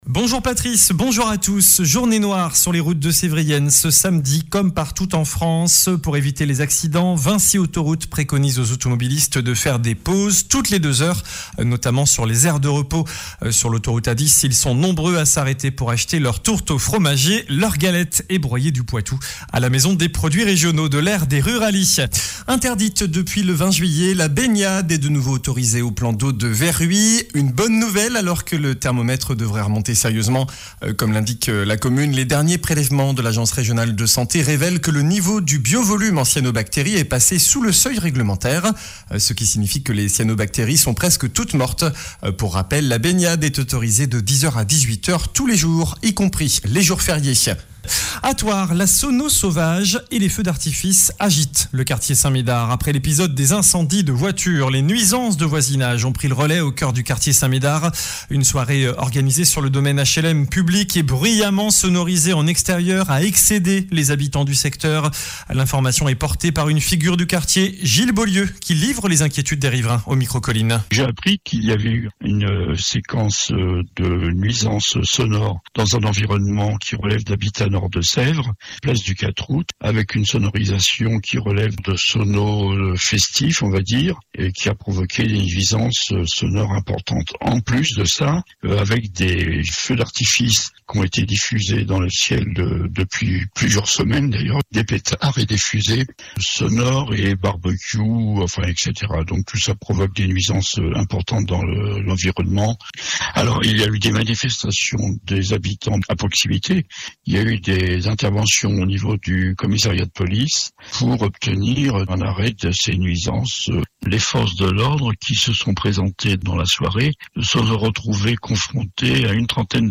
JOURNAL DU SAMEDI 05 AOÛT